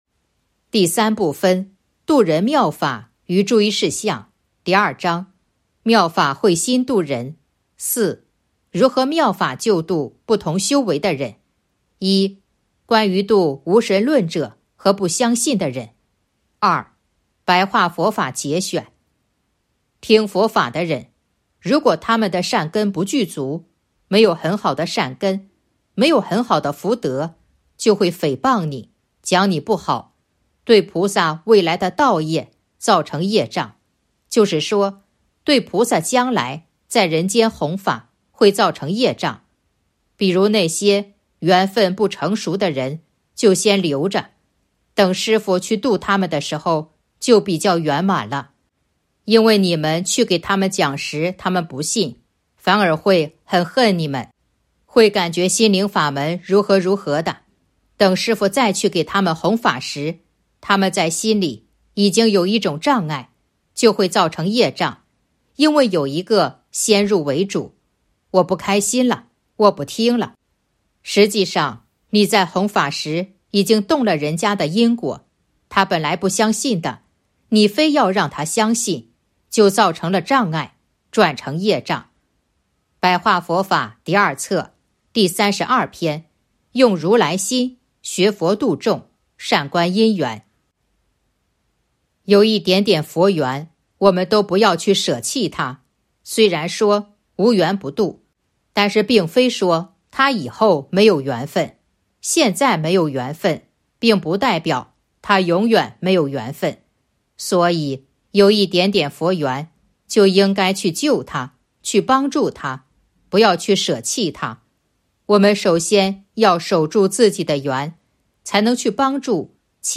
白话佛法节选《弘法度人手册》【有声书】